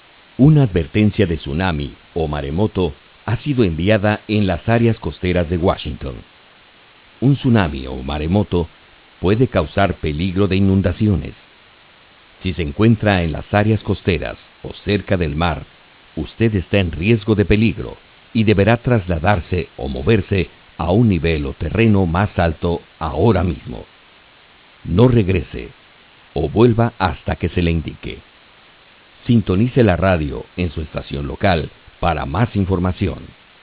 Evacuation Siren
Actual Message